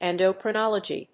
音節en・do・cri・nol・o・gy 発音記号・読み方
/èndəkrɪnάlədʒi(米国英語), ˌendəʊkrʌˈnɑ:lʌdʒi:(英国英語)/